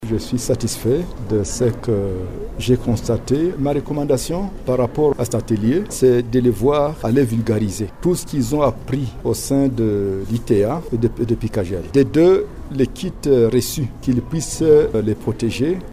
La cérémonie de leur décoration a été organisée le vendredi 24 mai 2024 dans la salle de conférence de l’Ecole Enfant Internationale du Monde situé sur avenue Résidence dans la commune d’IBANDA.
Le ministre provincial de la culture David MUBALAMA qui a clôturé la cérémonie exhorte les bénéficiaires à travailler dur car l’espoir du pays repose sur les jeunes.